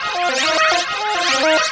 Index of /musicradar/rhythmic-inspiration-samples/140bpm
RI_ArpegiFex_140-02.wav